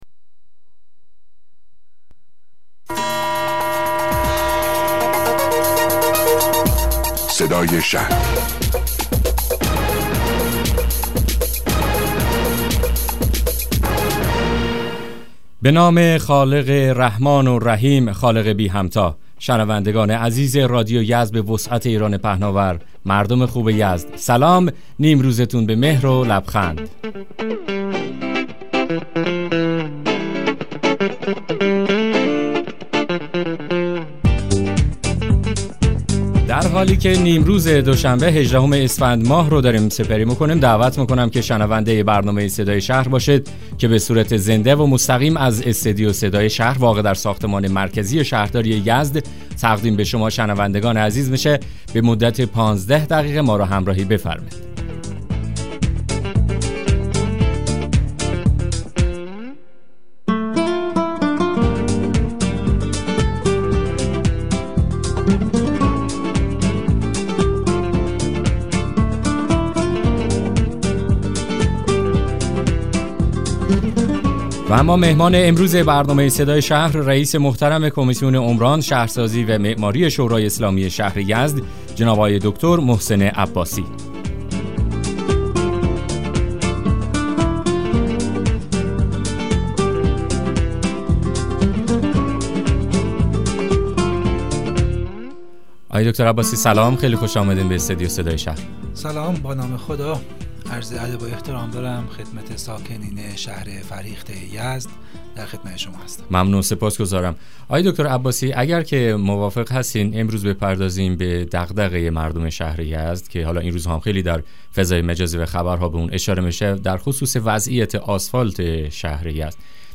مصاحبه رادیویی برنامه صدای شهر با حضور دکتر محسن عباسی عضو شورا و رییس کمیسیون عمران شورای شهر یزد